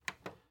VHS_Button.wav